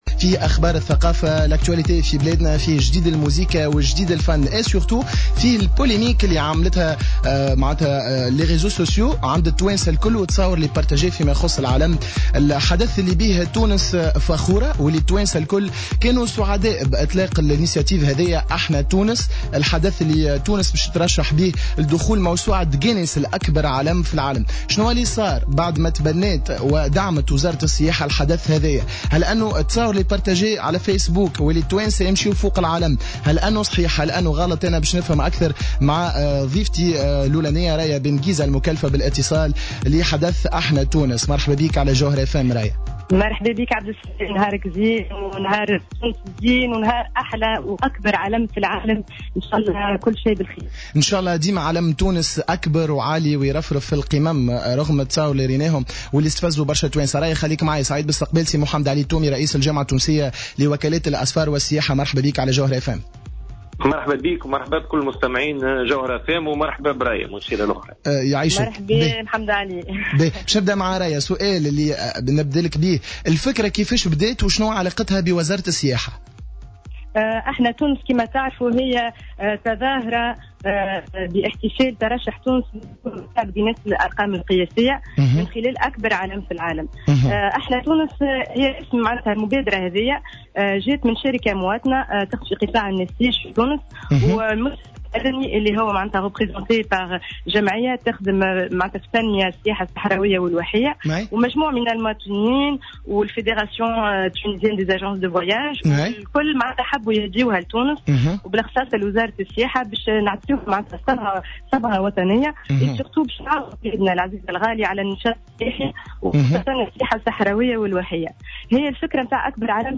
مداخلة على راديو جوهرة أف أم